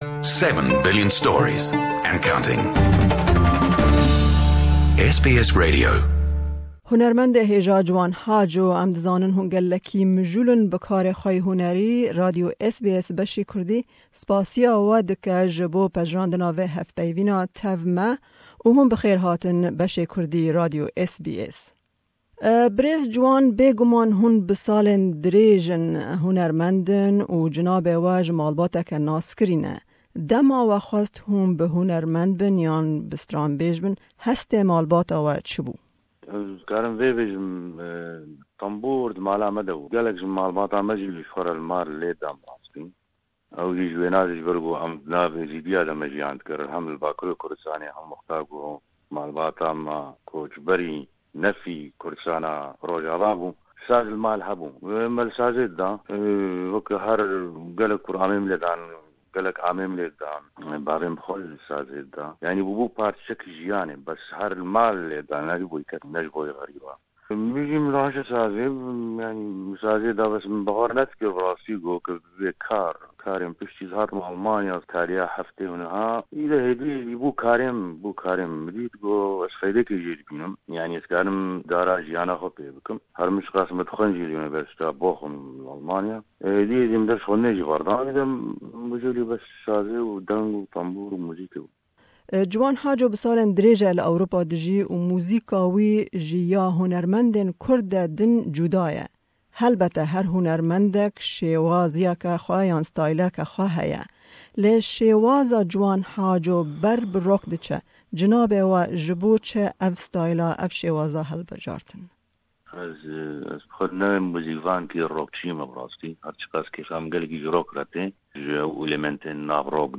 Well known Kurdish singer and musician Ciwan Haco speaks about his music and the role music plays in society.